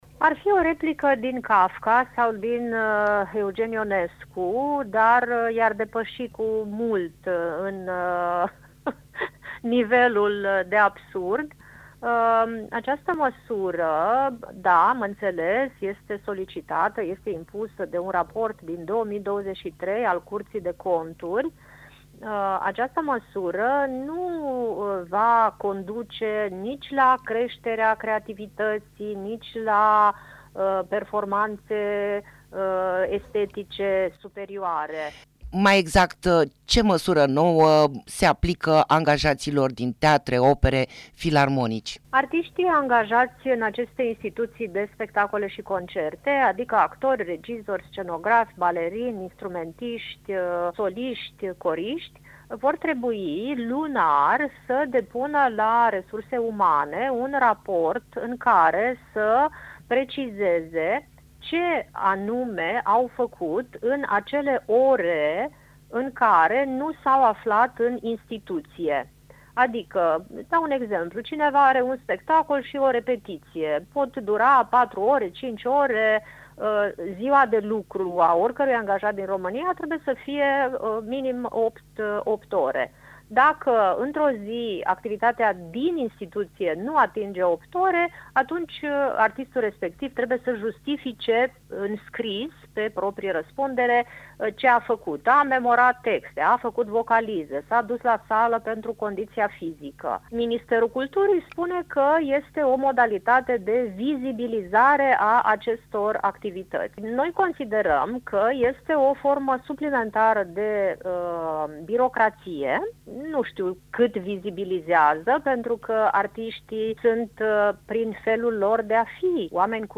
Interviu-actori-birocratie.mp3